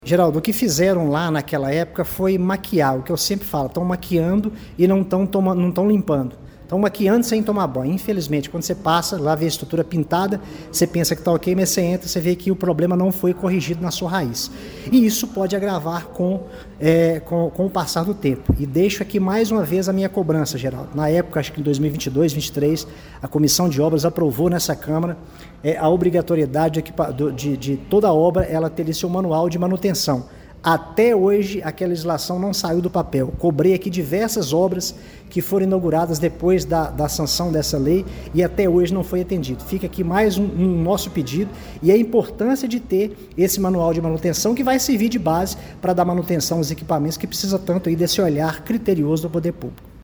A situação do Centro Municipal de Educação Infantil Prefeito José Gentil de Almeida, conhecido como Gentilzinho, no bairro Padre Libério, foi levada à tribuna da Câmara Municipal de Pará de Minas durante a reunião ordinária desta terça-feira (19).